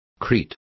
Complete with pronunciation of the translation of crete.